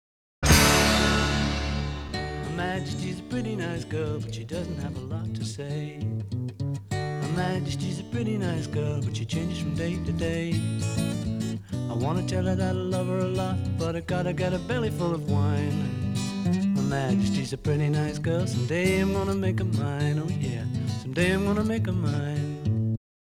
Bug 14552 – WAV-PCM Native causing white noise / popping between tracks
I've reproduced this bug by using dbpoweramp to rip an album to WAV and scan it into SbS 7.4.x. Played by SbS through either an SB2 or SB Touch, there's an audible pop at the end of each track.